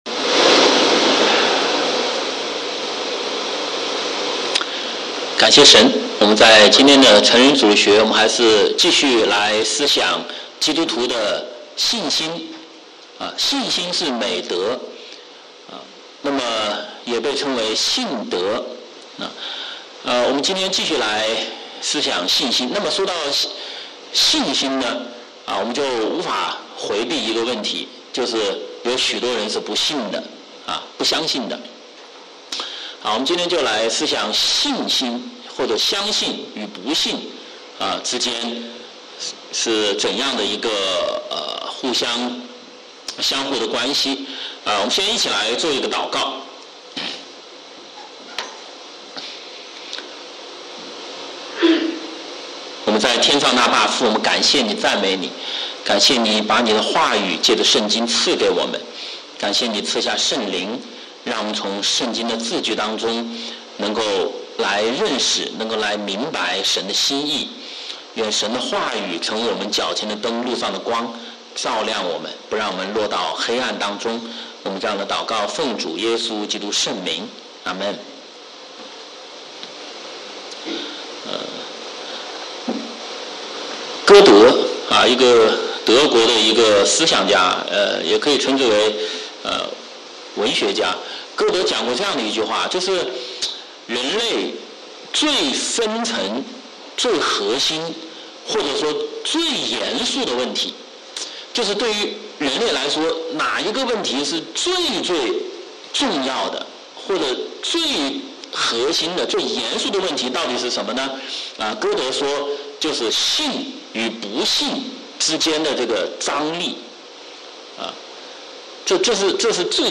主日学